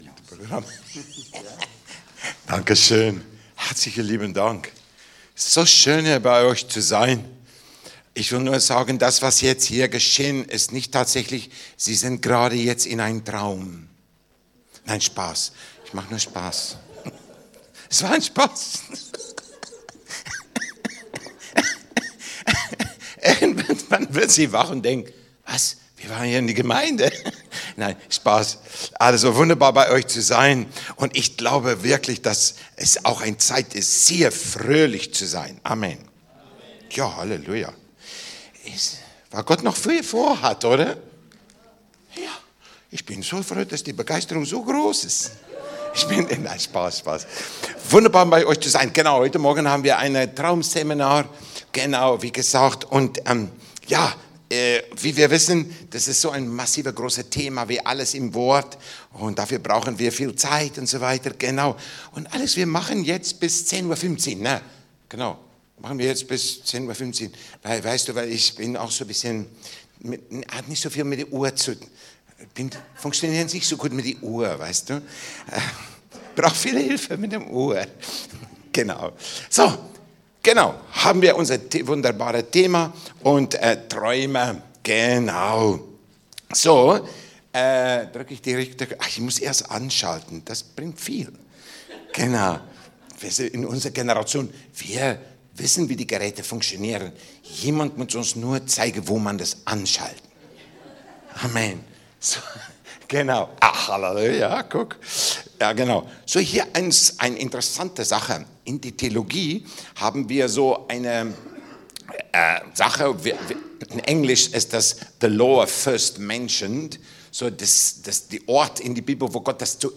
21.02.2025, 19:00 Uhr | Workshop Biblische Traumdeutung (Teil 1)